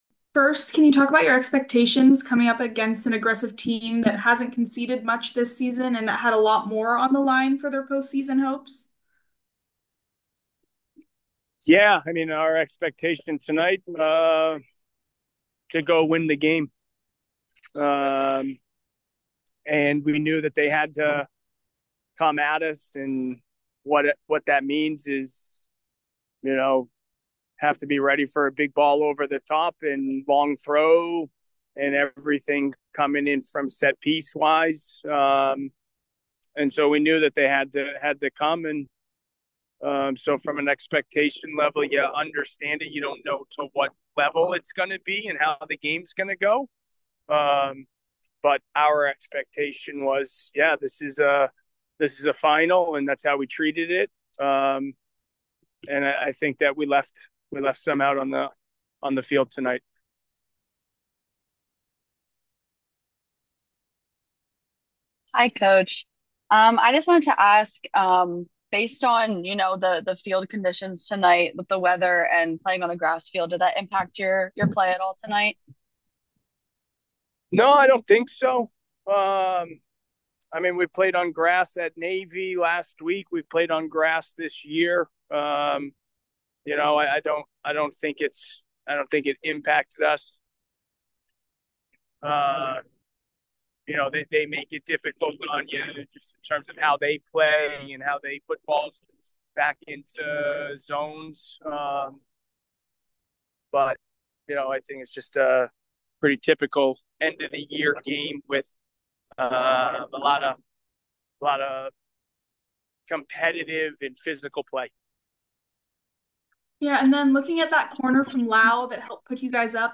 Holy Cross Postgame Interview